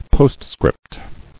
[Post·Script]